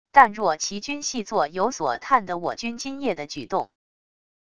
但若其军细作有所探得我军今夜的举动wav音频生成系统WAV Audio Player